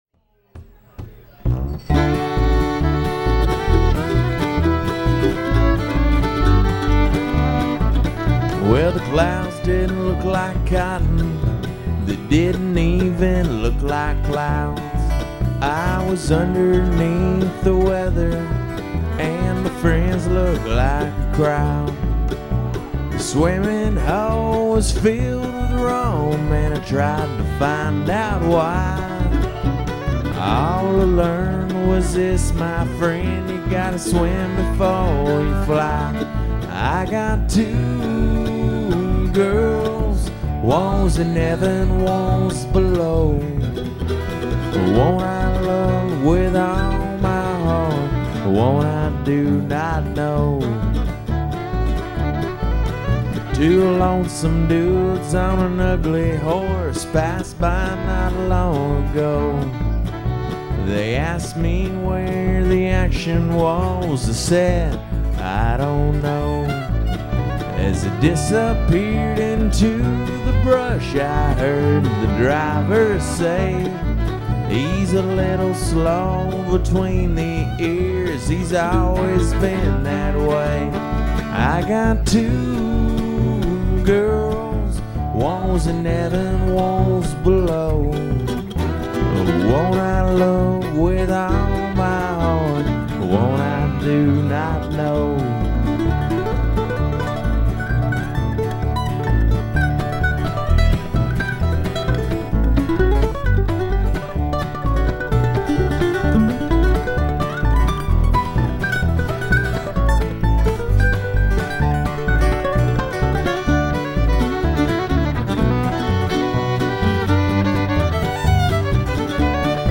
Nutty Brown Cafe Austin, TX
Guitar
Fiddle, Background Vocals
Mandolin